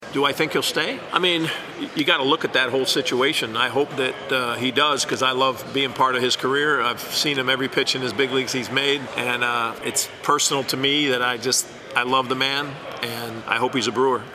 Peralta still with the team: Brewers manager Pat Murphy spoke while at baseball’s winter meetings.